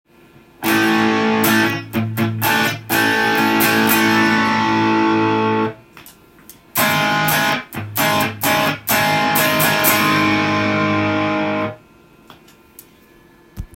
１の設定でエフェクターを踏み込むと
一気に半音下げに音が変化しました。
音質的には確かに劣化少し見られますが